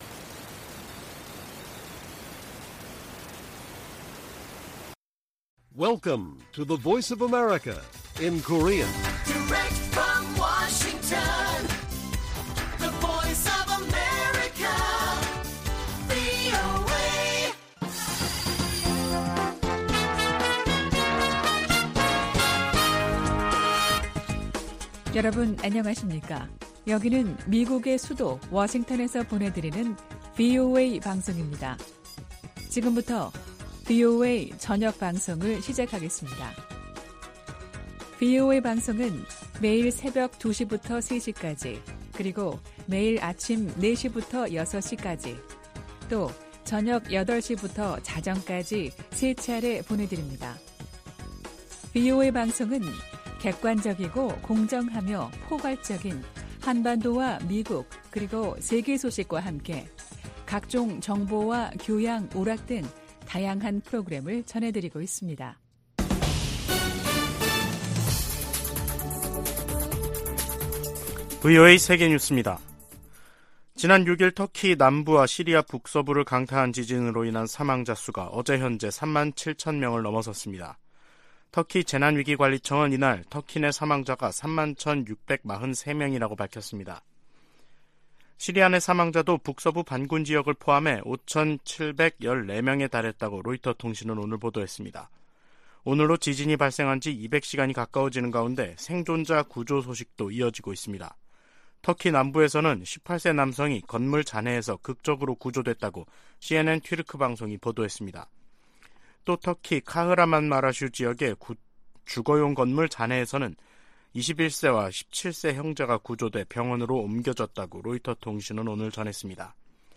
VOA 한국어 간판 뉴스 프로그램 '뉴스 투데이', 2023년 2월 14일 1부 방송입니다. 미국과 한국, 일본의 외교 차관들이 워싱턴에서 회의를 열고 북한의 핵과 미사일 위협에 대응해 삼각 공조를 강화하기로 했습니다. 미국 정부는 중국 등에 유엔 안보리 대북 결의의 문구와 정신을 따라야 한다고 촉구했습니다. 백악관은 중국의 정찰풍선이 전 세계 수십 개 국가를 통과했다는 사실을 거듭 확인했습니다.